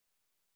♪ keŋgaṛi